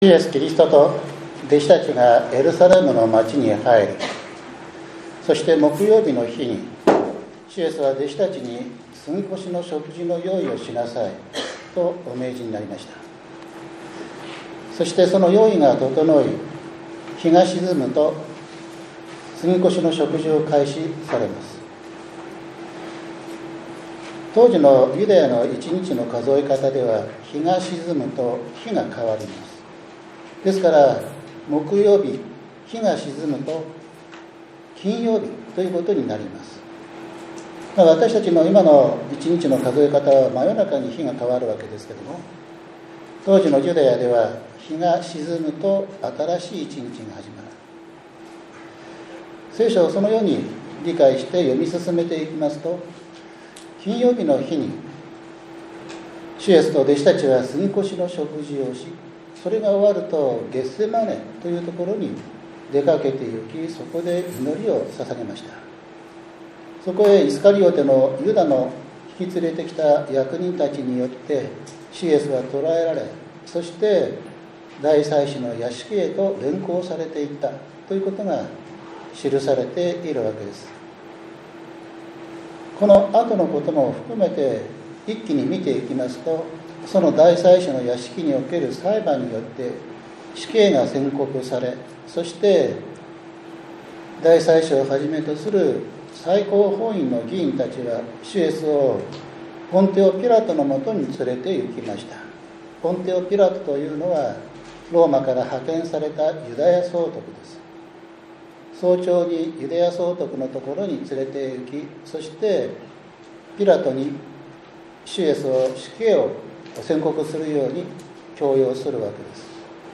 ３月１６日（日）主日礼拝